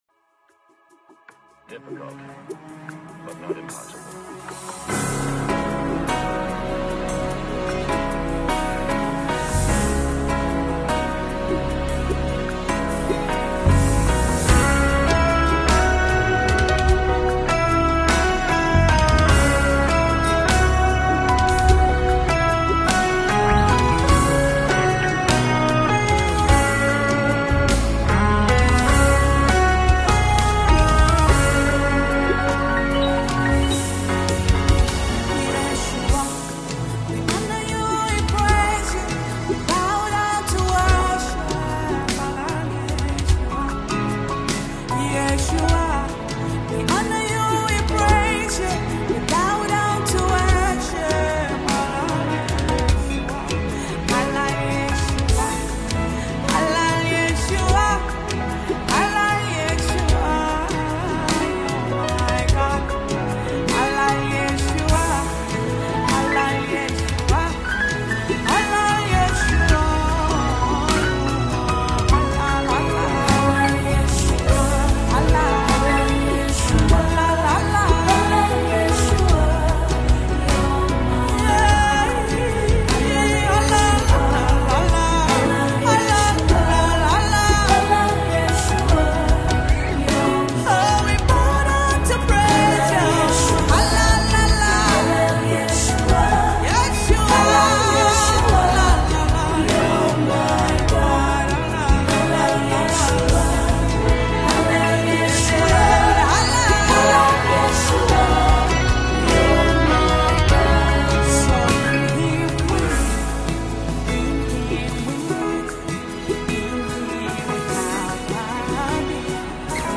Tanzanian gospel/worship single
soulful vocals and passionate worship delivery